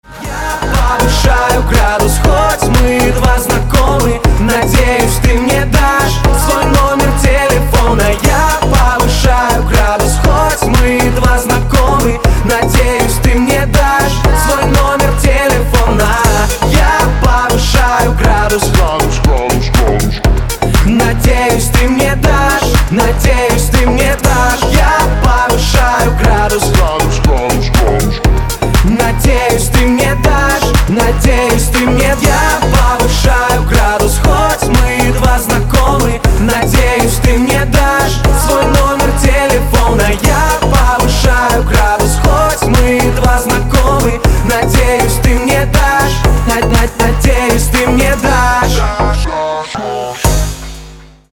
• Качество: 320, Stereo
поп
мужской вокал
громкие
dance